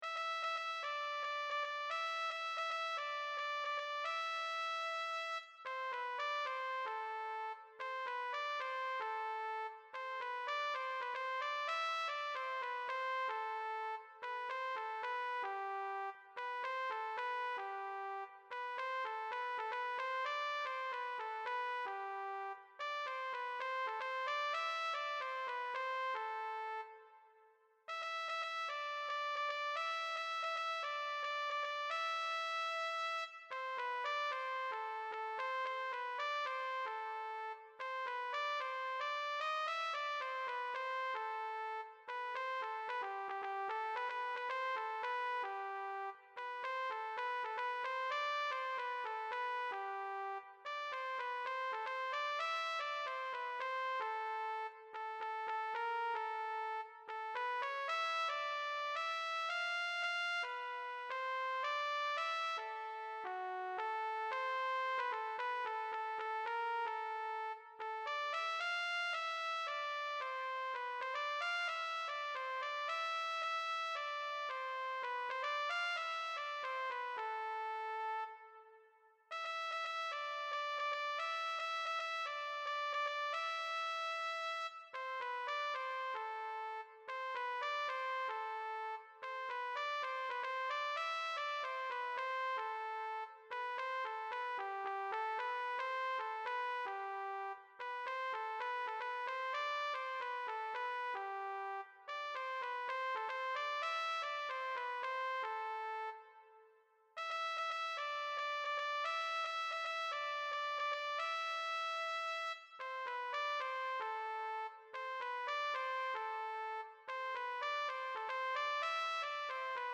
Grabación MIDI de la voz de SOPRANO
para coro femenino a 3 voces SMA
Audio de soprano. Sonido MIDI.